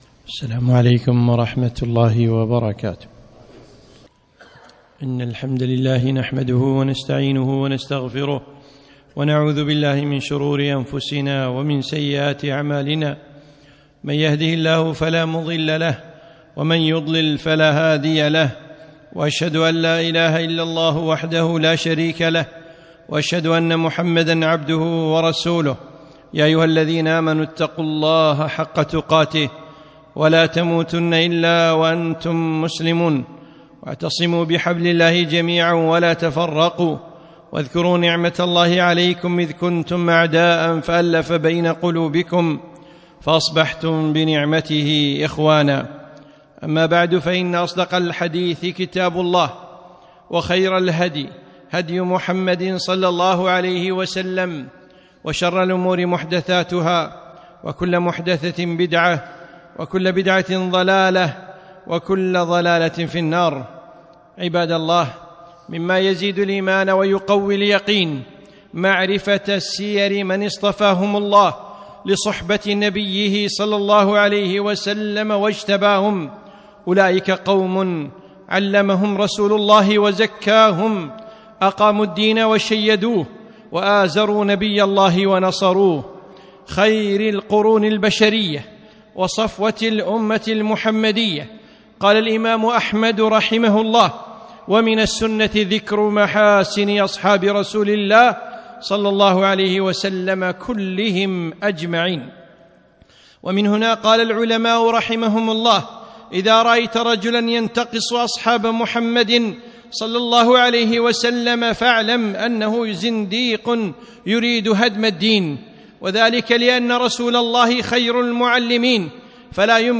خطبة - ذو النورين